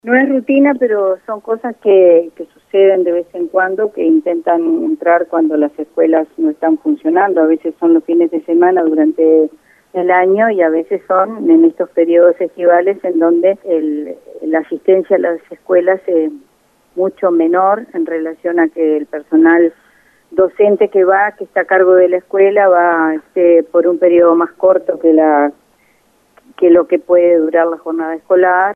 Mirtha Frondoy, consejera de Primaria, dijo a El Espectador que durante el verano los robos son más frecuentes porque es cuando hay menos movimiento en las instituciones.
Mirtha Frondoy en 810 VIVO